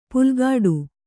♪ pulgāḍu